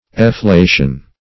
Search Result for " efflation" : The Collaborative International Dictionary of English v.0.48: Efflation \Ef*fla"tion\, n. The act of filling with wind; a breathing or puffing out; a puff, as of wind.